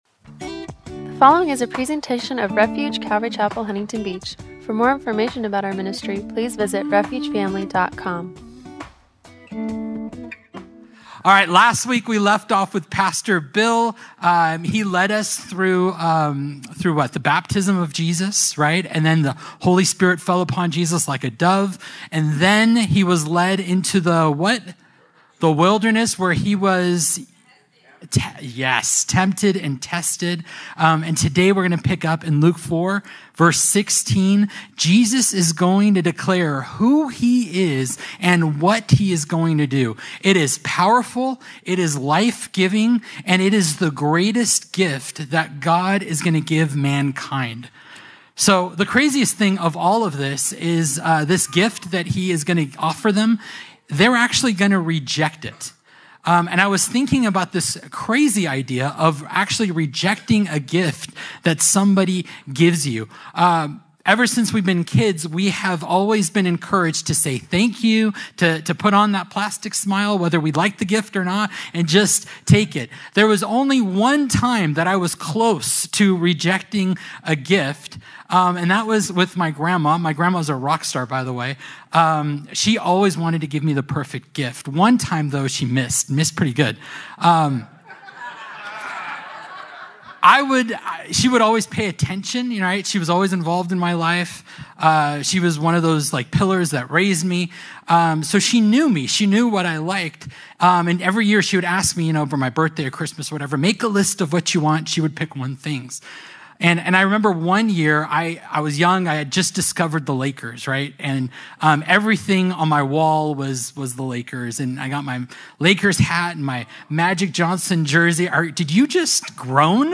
Service Type: Good Friday Service